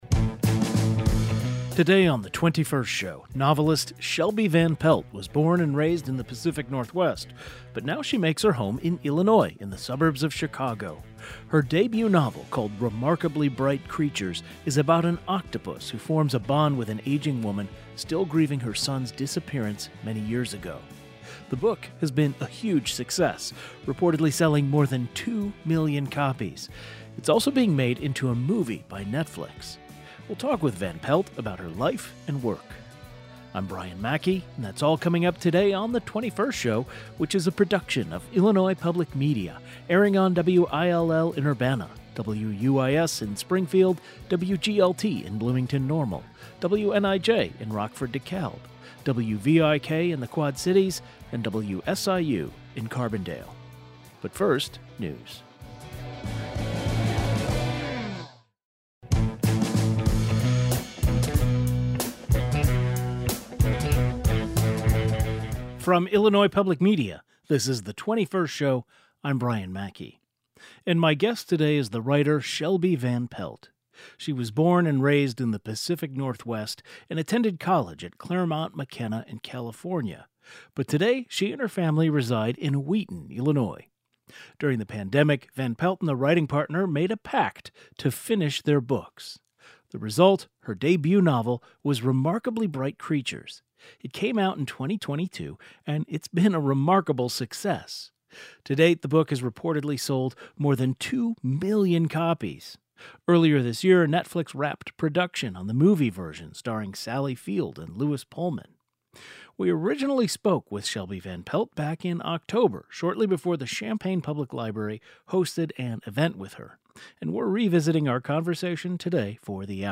Today's show included a rebroadcast of the following "best of" segment, first aired October 3, 2025: Illinois author Shelby Van Pelt on the smash success of ‘Remarkably Bright Creatures’